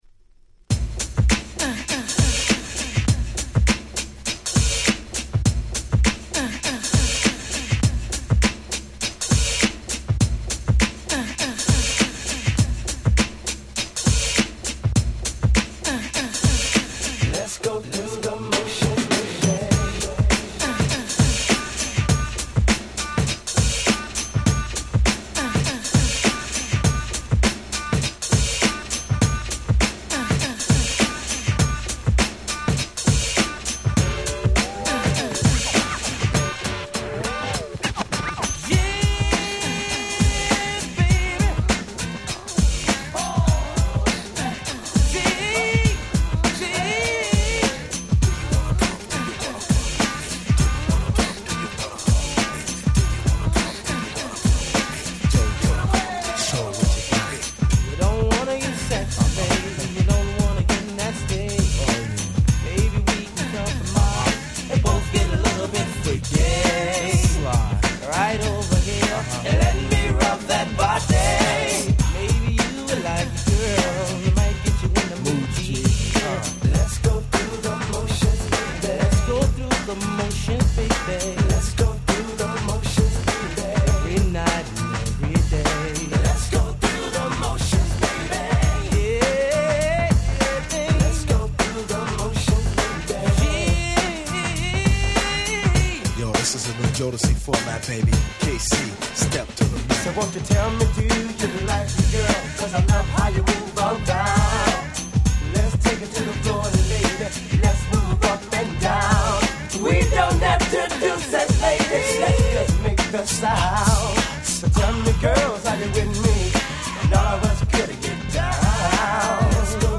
ウィッキド ウィッキード ミックス物 90's R&B Hip Hop 勝手にリミックス 勝手にRemix